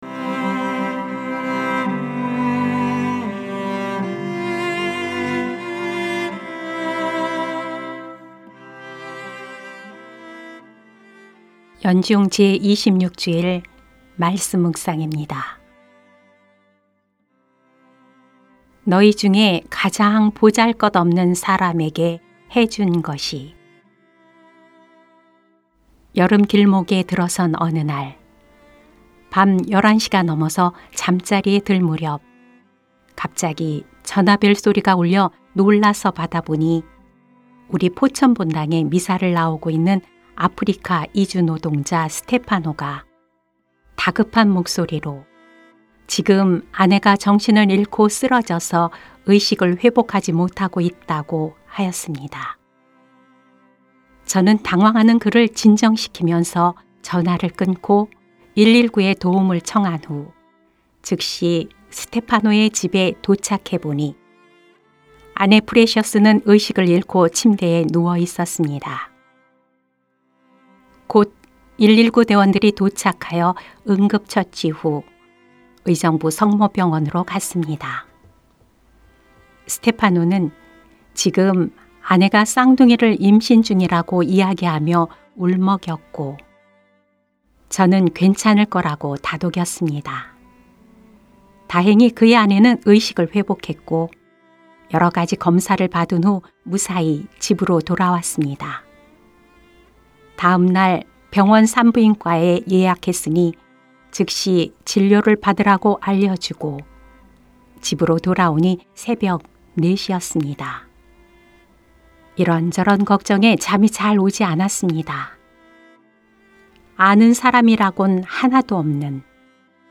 2021년 9월 26일 연중 제26주일 - 말씀묵상 듣기(☜파란색 글씨를 클릭하세요)